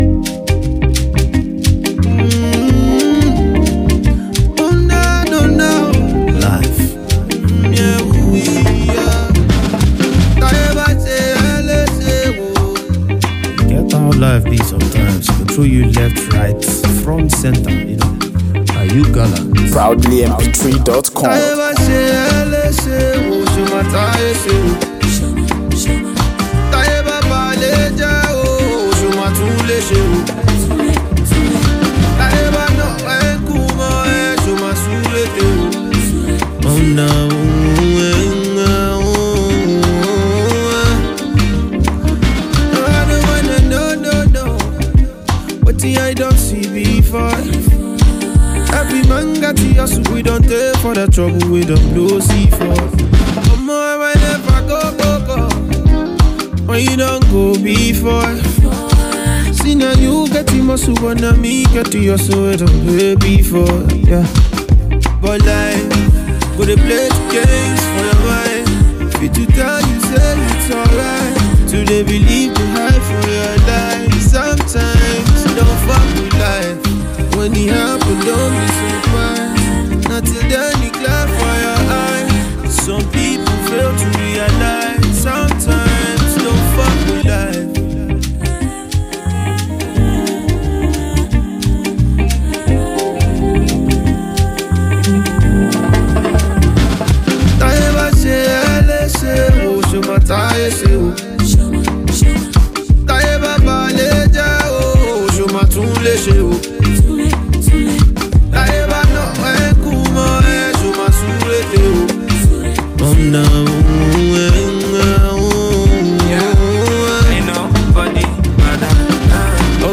had vocal assistance from Nigerian rapper,